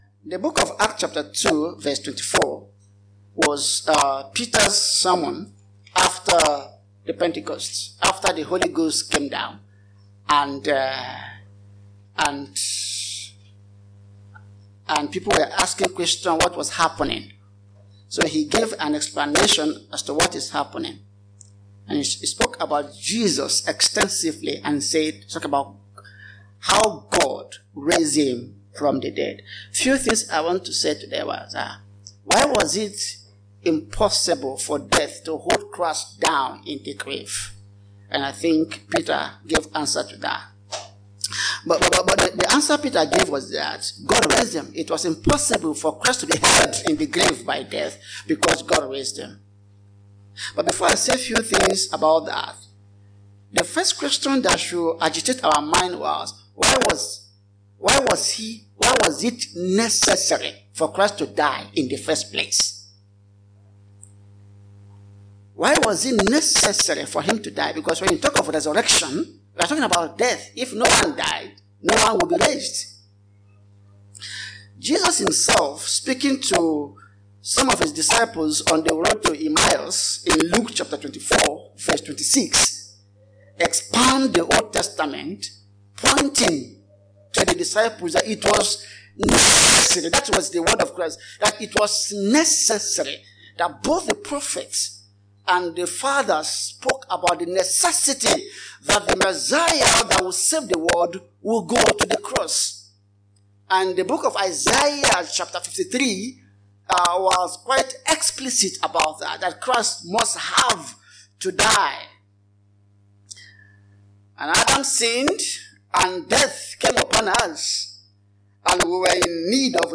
TBC | Sermon